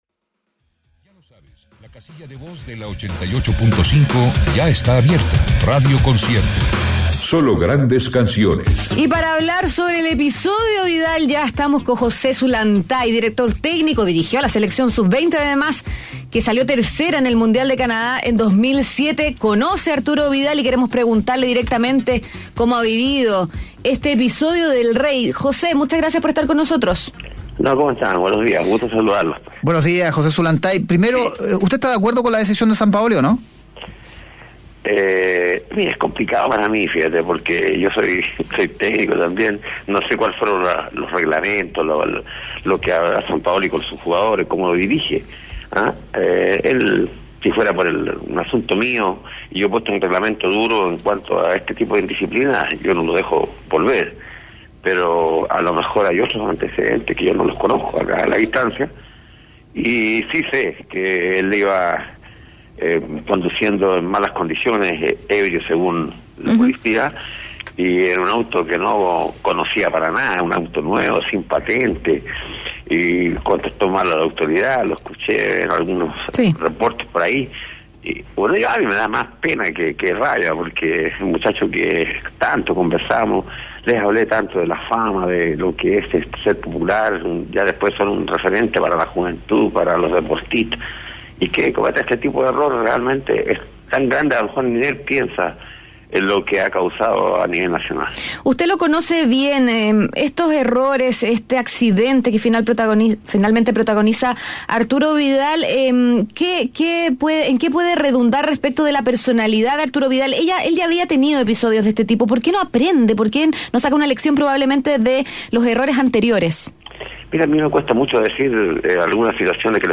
Escucha la entrevista completa realizada en Mañana Será Otro Día: